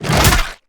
Sfx_creature_rockpuncher_chase_os_03.ogg